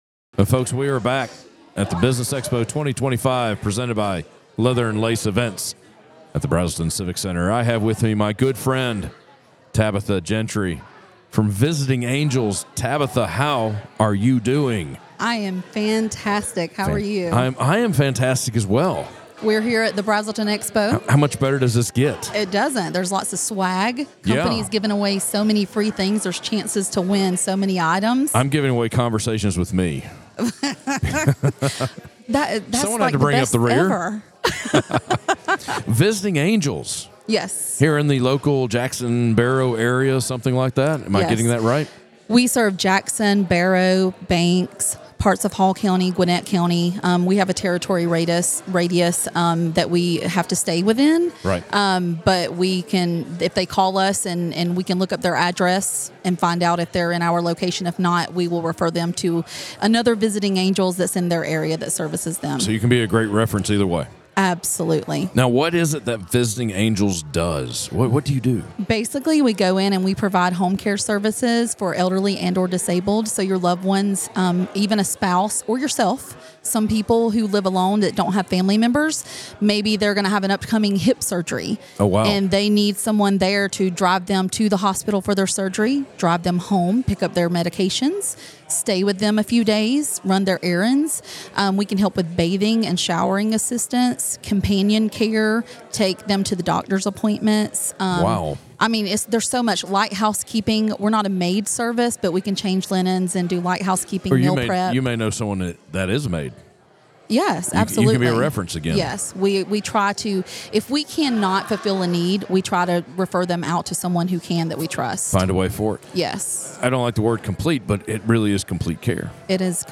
Business Expo 2025 presented by Leather & Lace Events at the Braselton Civic Center
Northeast Georgia Business RadioX – the official Podcast Studio of the Business Expo 2025